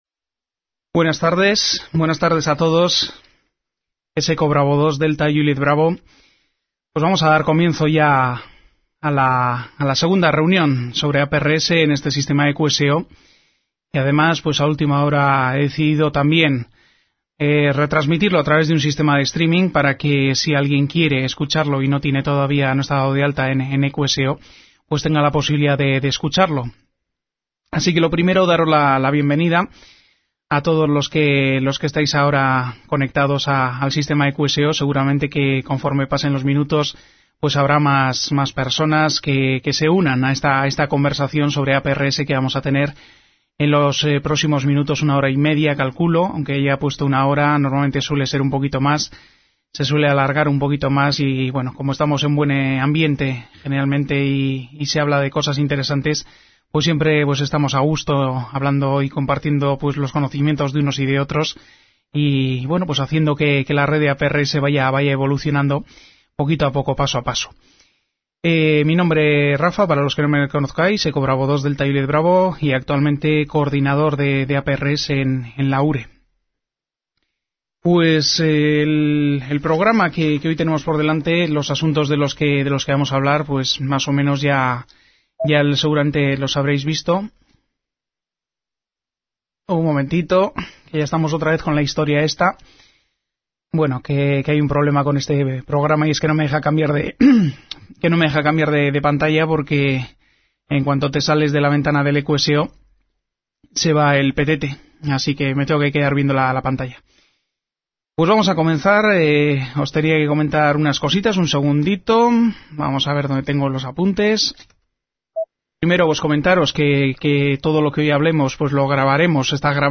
En la tarde del sábado 7 de mayo de 2011, nos volvimos a juntar mediante el sistema e-QSO para seguir hablando de APRS… en ésta ocasión con unos temas ya centrados en la propia red y la adecuación de la normativa.